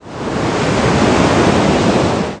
seawave.mp3